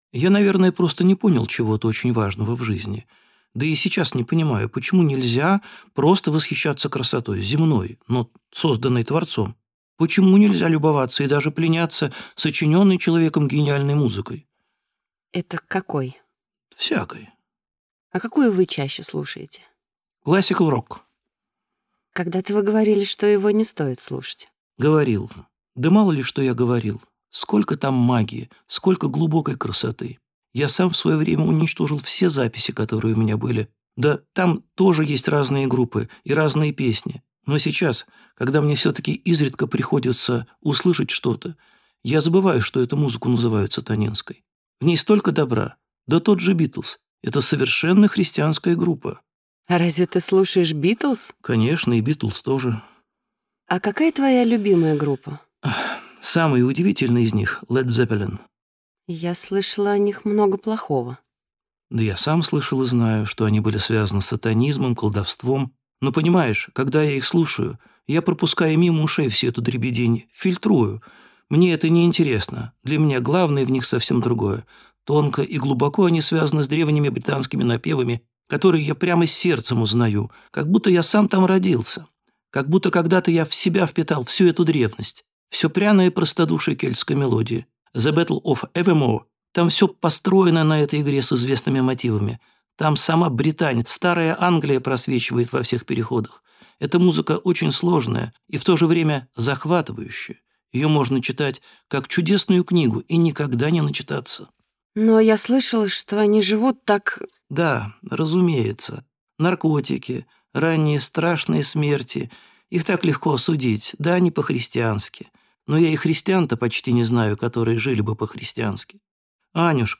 Aудиокнига Бог дождя Автор М. А. Кучерская Читает аудиокнигу М. А. Кучерская.